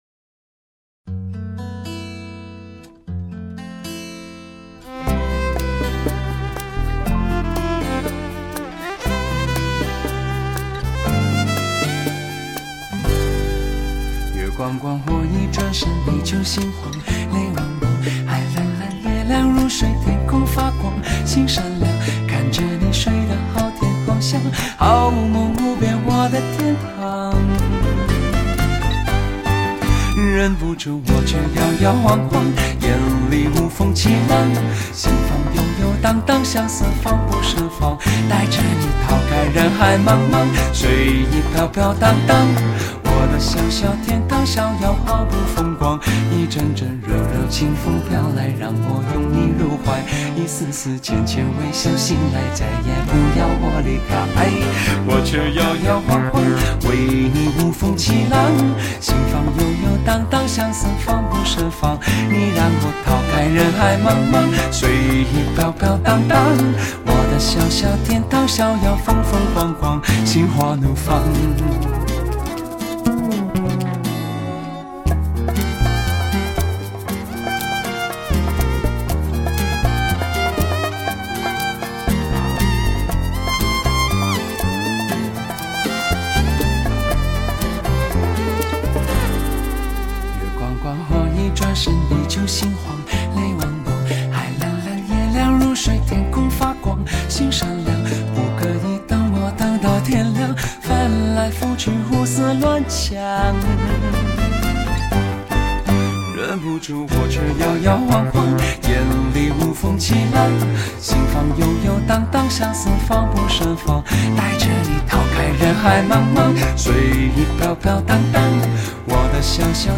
真音乐，好自在，最Easy ，新编曲，新录音，新面目。